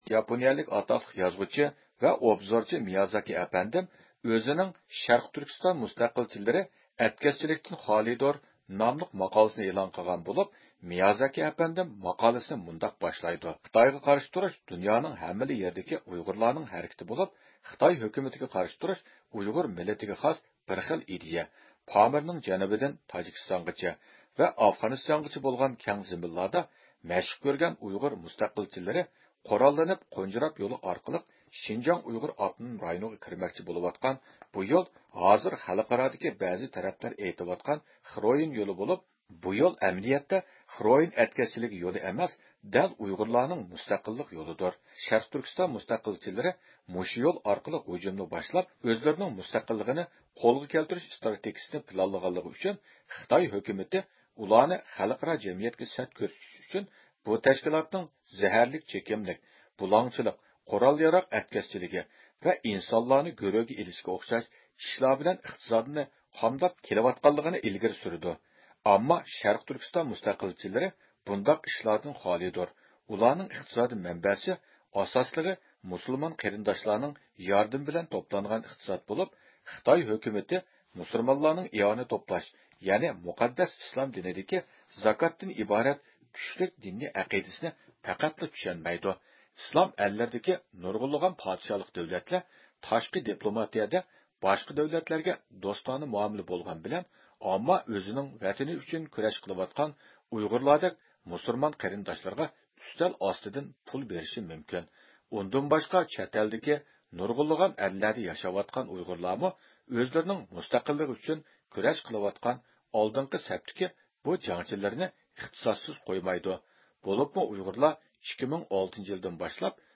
ئىختىيارىي مۇخبىرىمىز